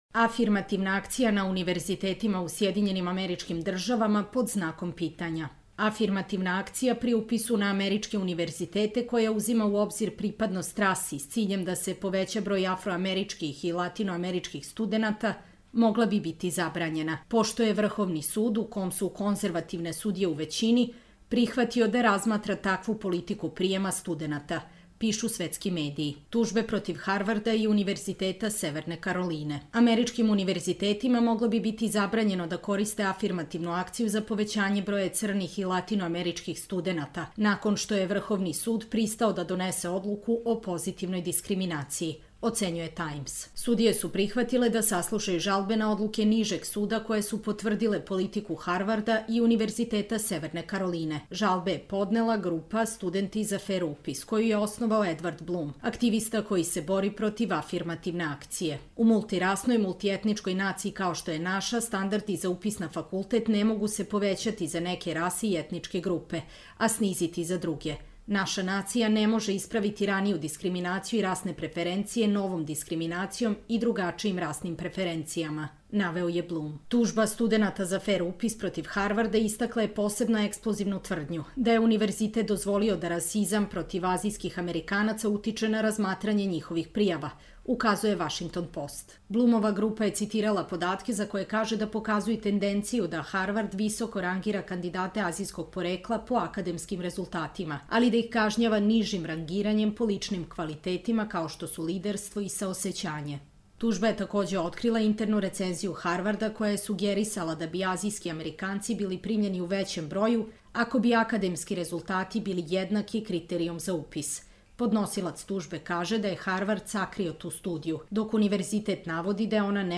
Čitamo vam: Afirmativna akcija na univerzitetima u SAD pod znakom pitanja